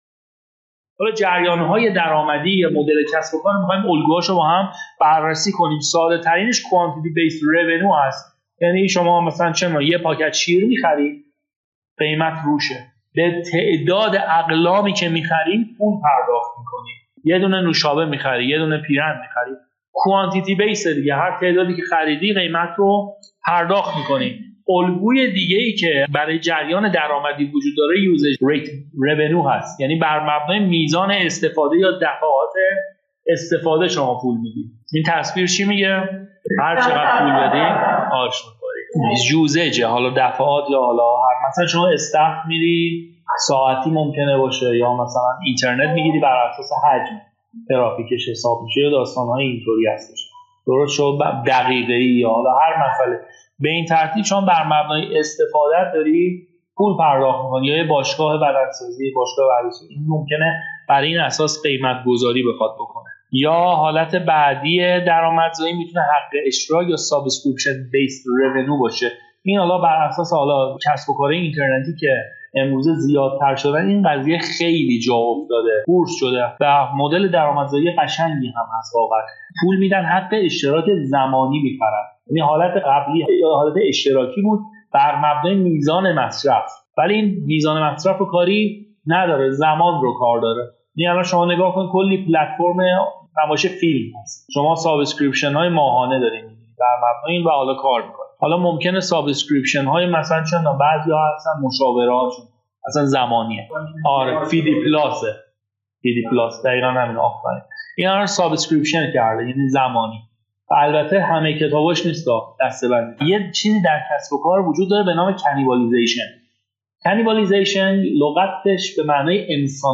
مدت زمان : 26 دقیقهاین قسمت از رادیو کارآفرینی برشی از کلاس درس مدل کسب و کار مربوط به اوایل ۱۴۰۰ می باشد.